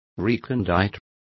Complete with pronunciation of the translation of recondite.